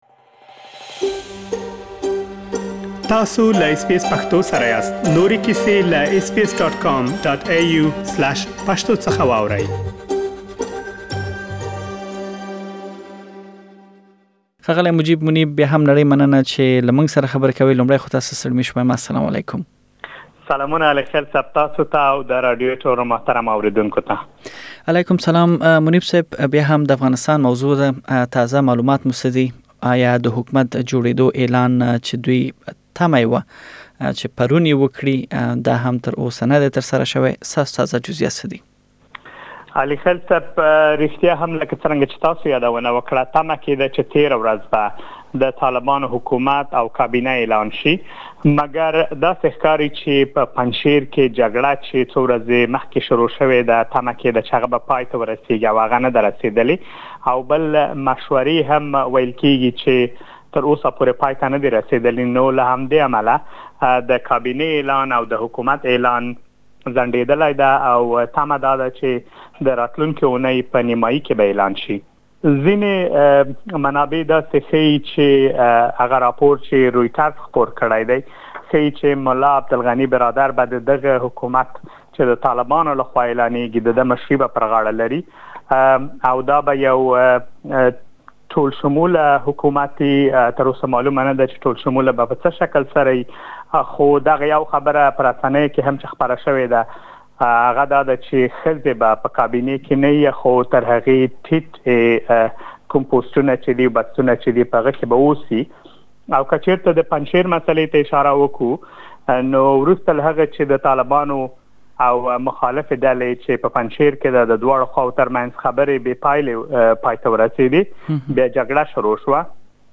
تېره اوونۍ په افغانستان کې یو زیات شمېر مهمې پیښې رامنځ ته شوي، د اوونۍ د مهمو پېښو په اړه مهم معلومات په دغه رپوټ کې اوریدلی شئ.
د اوونۍ د مهمو پېښو په اړه لاډېر معلومات په ترسره شوې مرکې کې اوریدلی شئ.